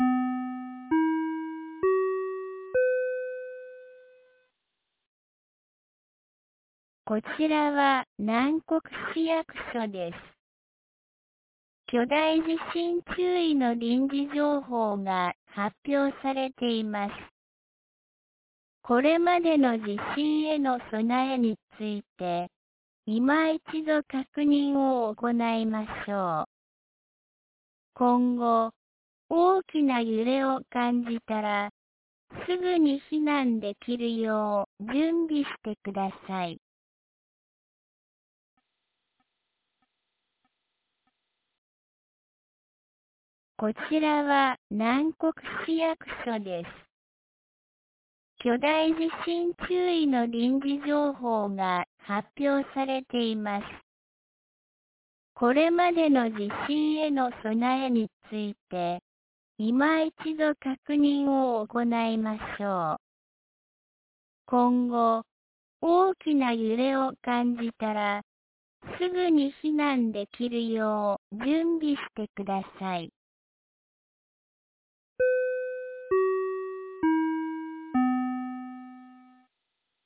2024年08月13日 13時01分に、南国市より放送がありました。